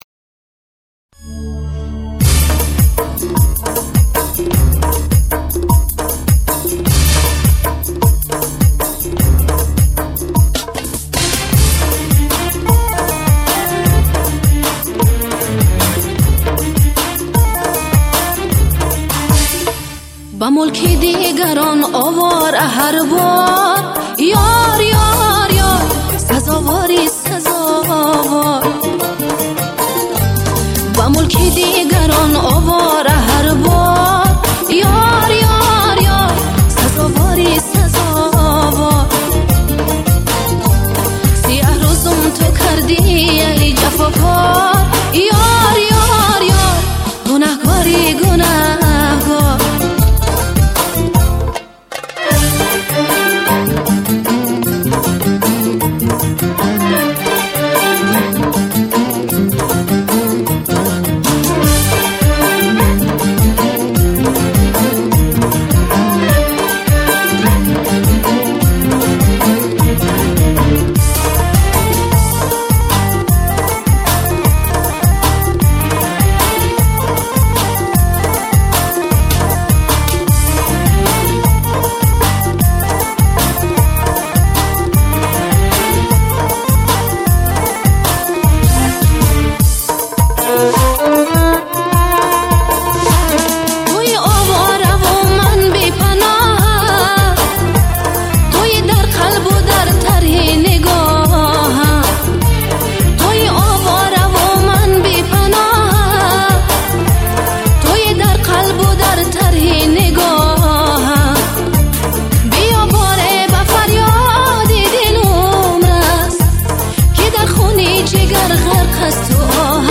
Главная » Файлы » Каталог Таджикских МР3 » Эстрада